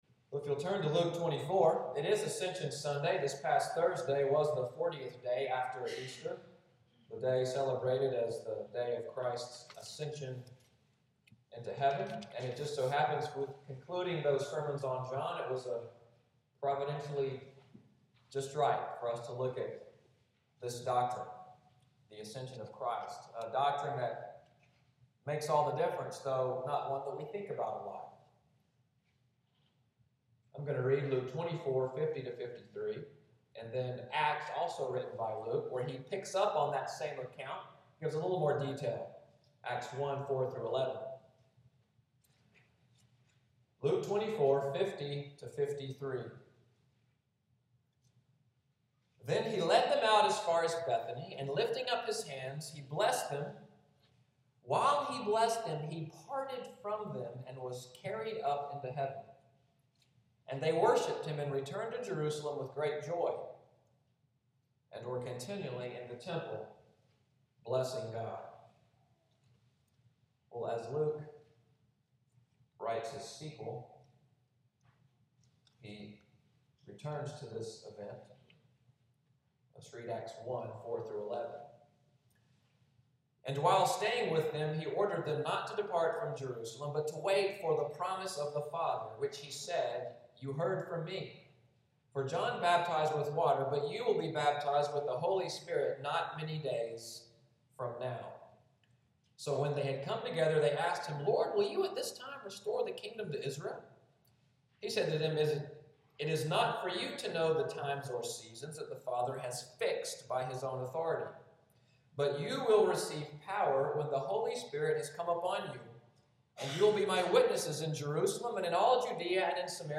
Sermon texts: Luke 24:50–53; Acts 1:4–11